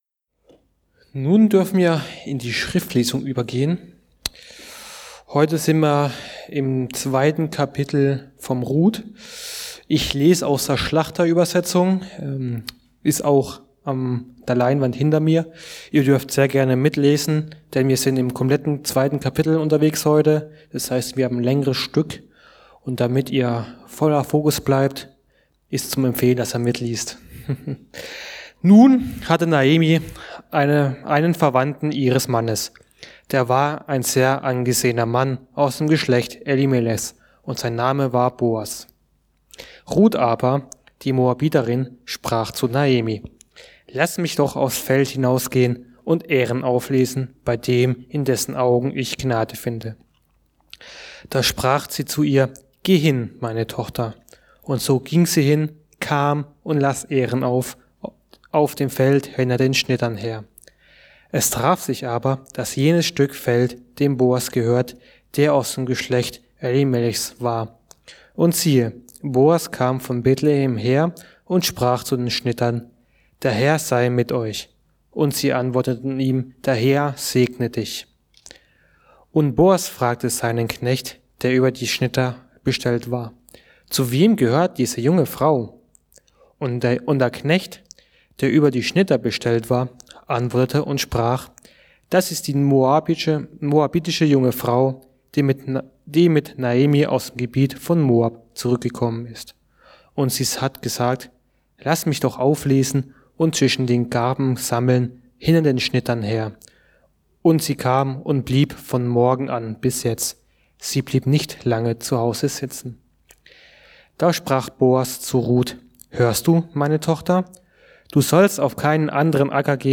Der Gott der Rettet ~ Mittwochsgottesdienst Podcast